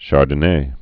(shärdn-ā, shärdn-ā)